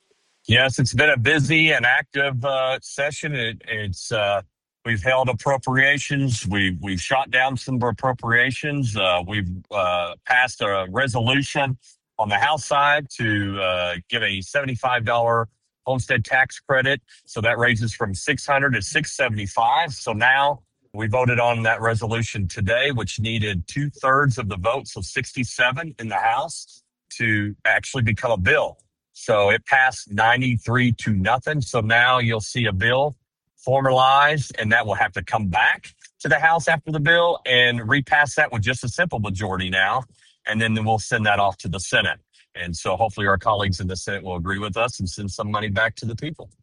KTLO, Classic Hits and the Boot News spoke with Rep. Painter who says the House passed a resolution to raise the credit by $75, increasing it from $600 to $675.